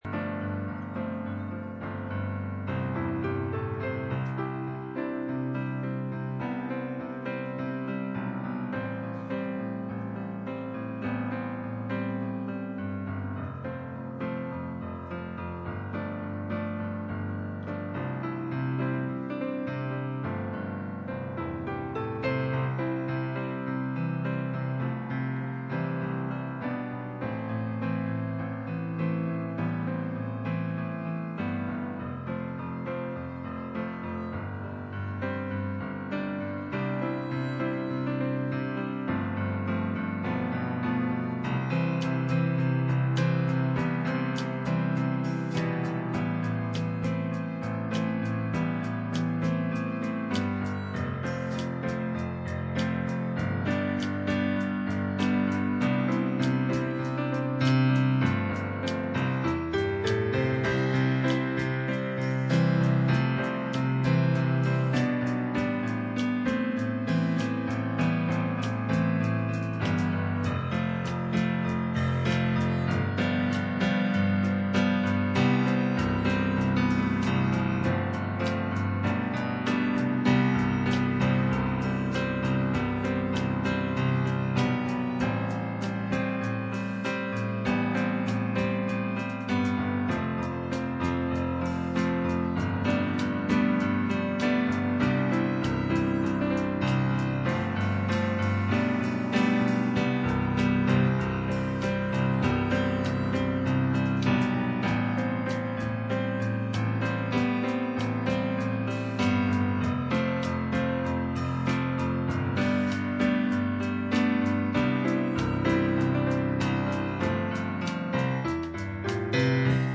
Colossians 1:26-27 Service Type: Sunday Morning %todo_render% « Youth Service